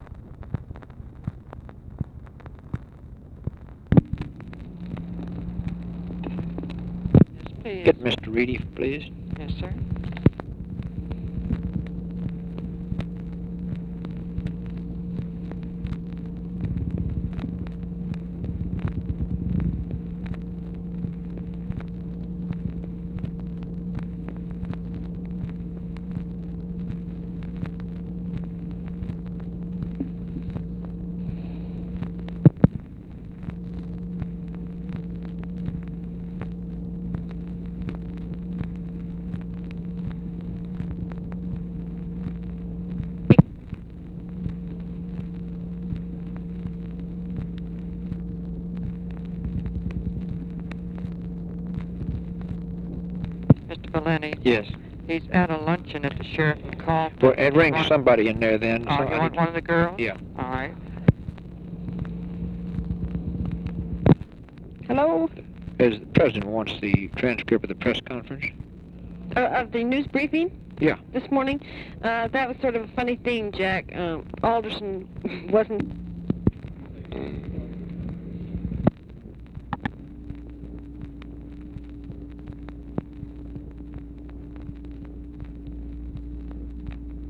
Conversation with JACK VALENTI and WHITE HOUSE PRESS OFFICE, May 27, 1964